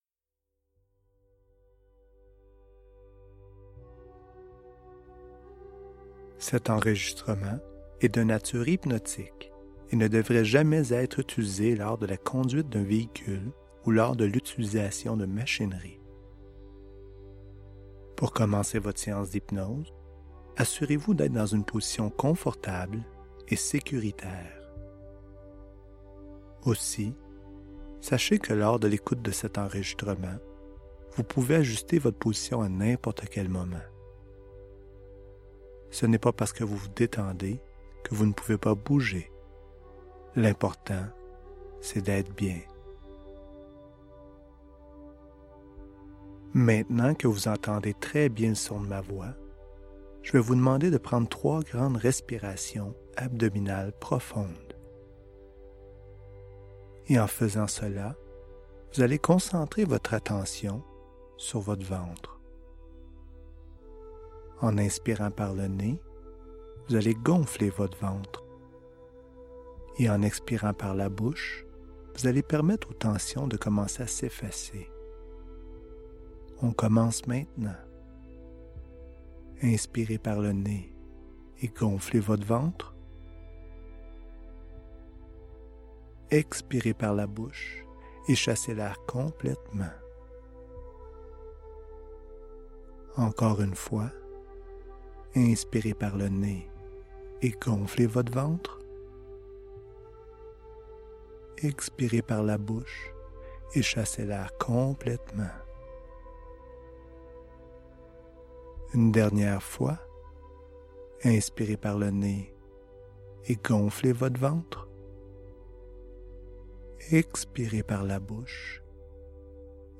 Voici tel que promis le lien pour télécharger le MP3 d’hypnose pour arrêter de fumer.
4-Mini-relaxation.mp3